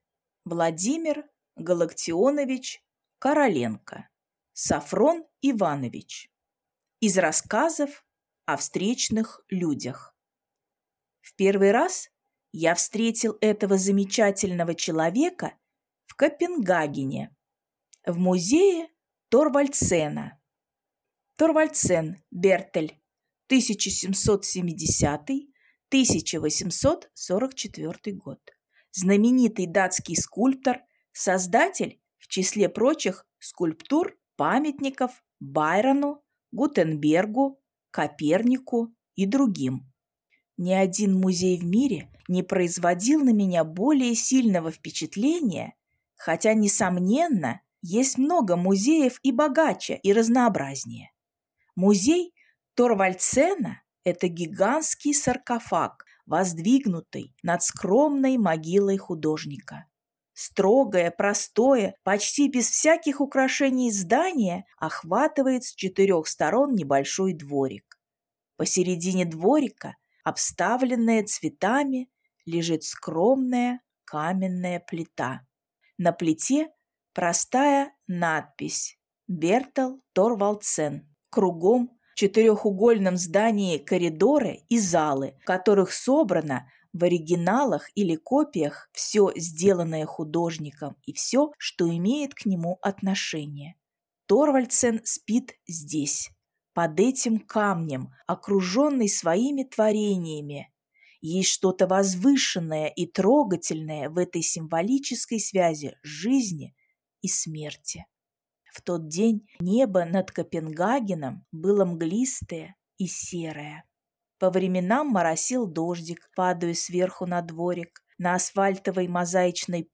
Аудиокнига Софрон Иванович | Библиотека аудиокниг